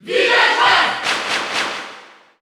File:Villager Female Cheer French PAL SSBU.ogg
Category: Crowd cheers (SSBU) You cannot overwrite this file.
Villager_Female_Cheer_French_PAL_SSBU.ogg